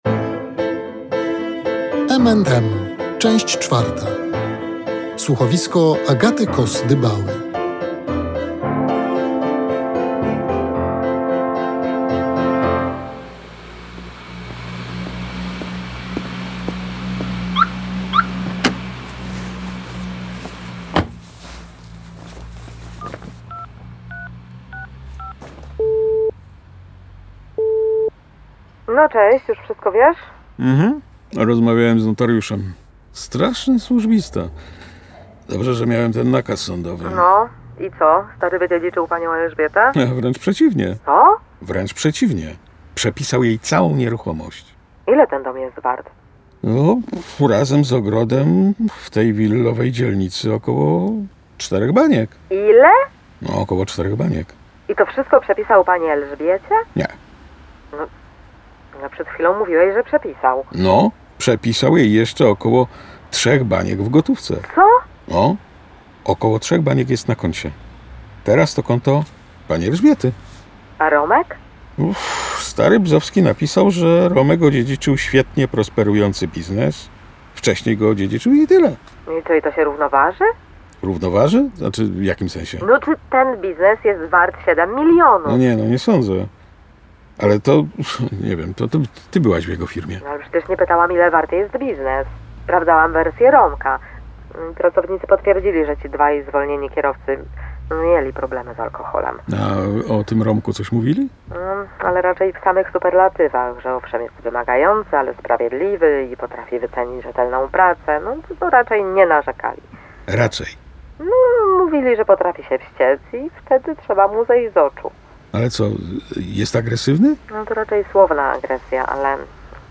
Pytania na te odpowiedzi znajdą Państwo w czwartym, finałowym odcinku słuchowiska „M&M”.